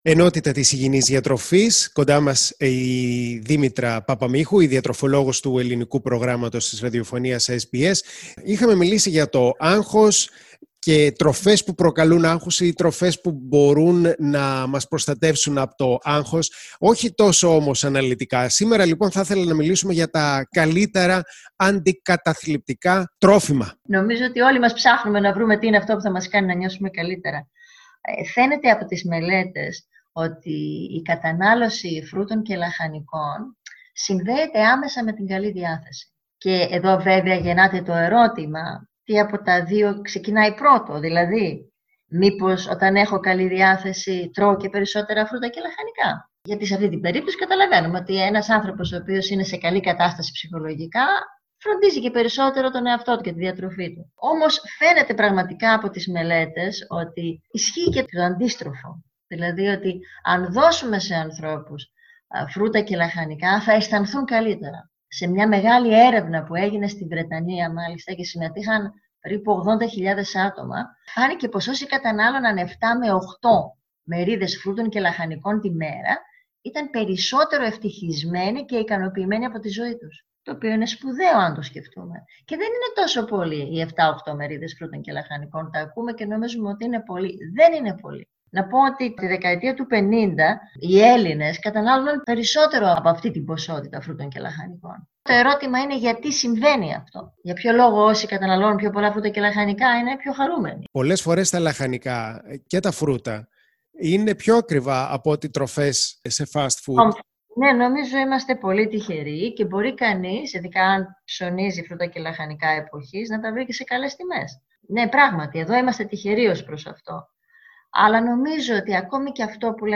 talks to SBS Greek about foods that could potentially can make us feel happier.